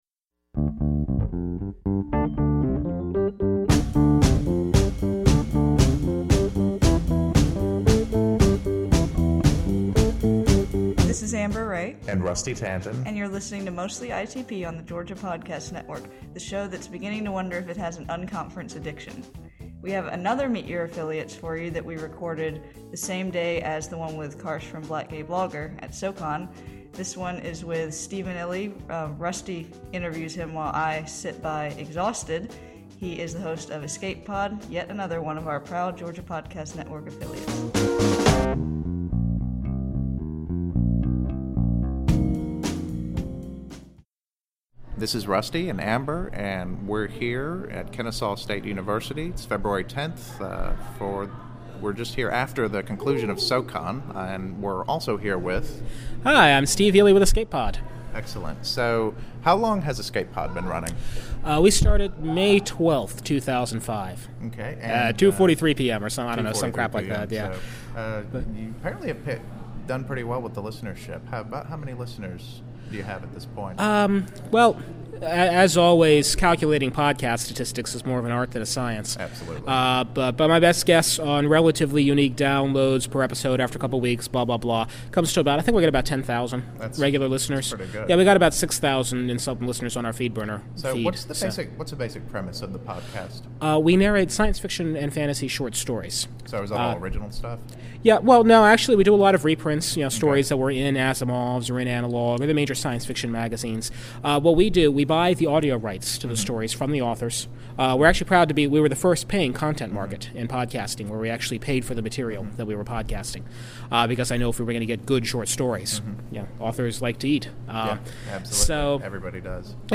interview
is the second we conducted at SoCon07